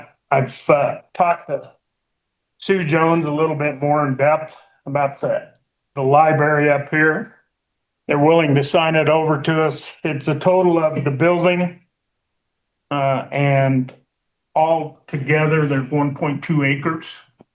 During Tuesday’s Saratoga Town Council meeting, Mayor Chuck Davis said he spoke to Commissioner Sue Jones, who informed him that the library sits on 1.2 acres of county-owned land.